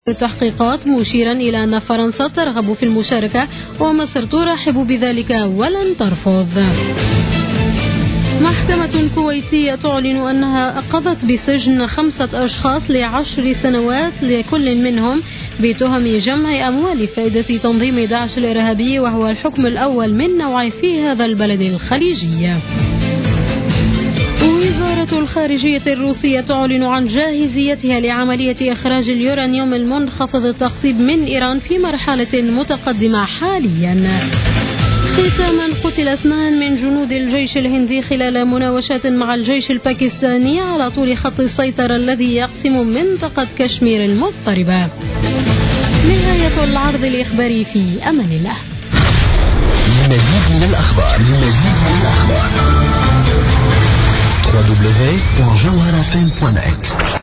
نشرة أخبار منتصف الليل ليوم الثلاثاء 03 نوفمبر 2015